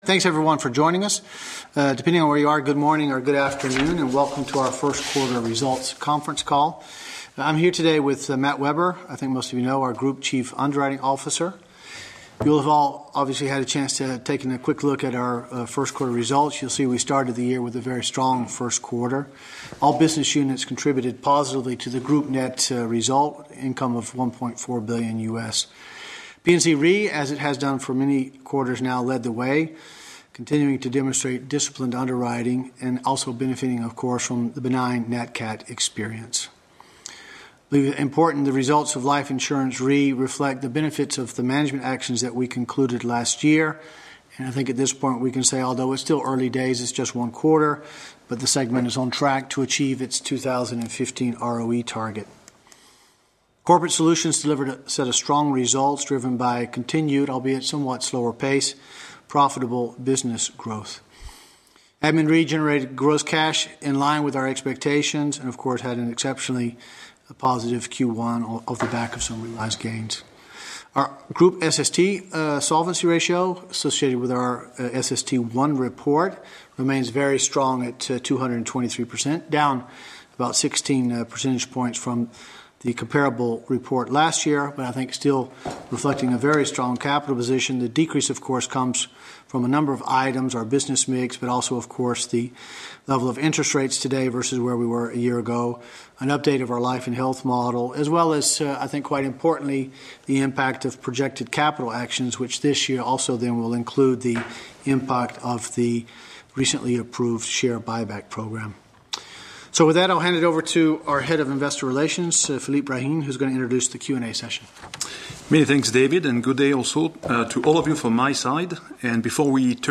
Analysts Conference call recording
2015_q1_qa_audio.mp3